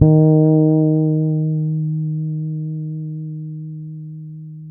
-MM JACO D#4.wav